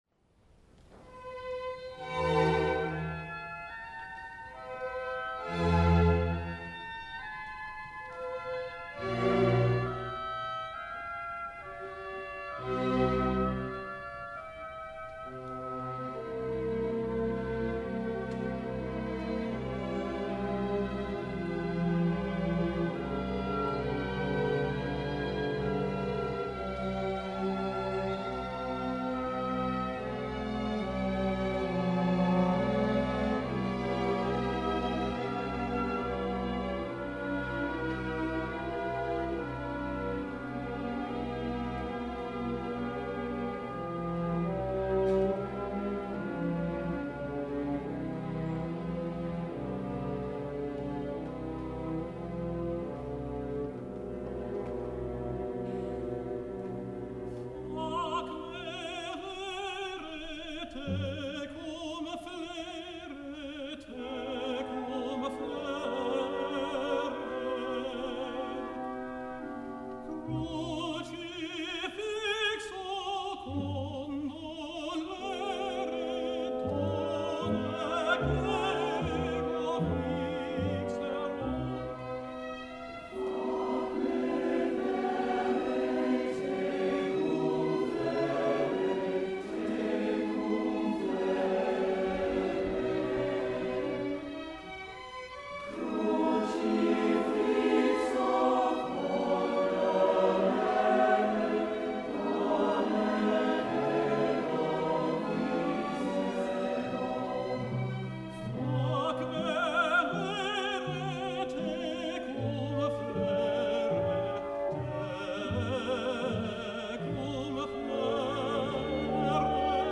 The Harvard Summer Chorus
Members of the Harvard Chamber Orchestra
Sanders Theatre, Cambridge MA   August 6, 1993